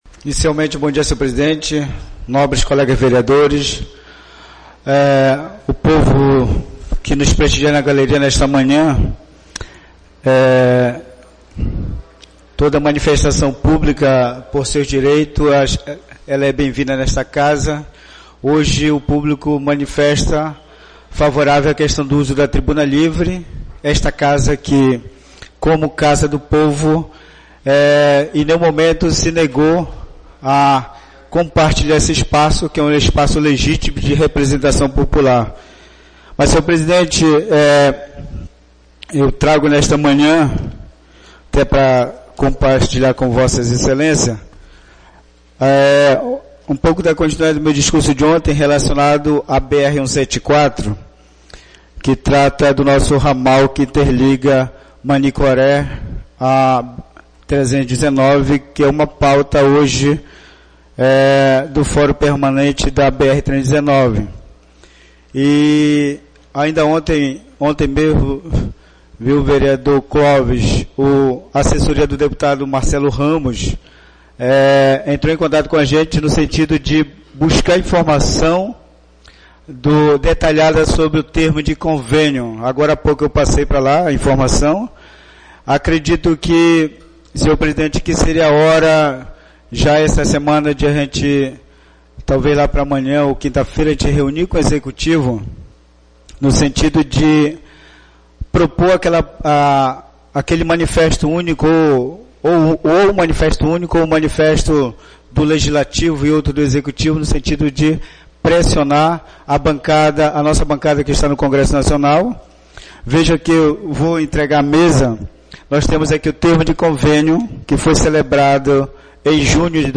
Oradores do Expediente (9ª Sessão Ordinária da 3ª Sessão Legislativa da 31ª Legislatura)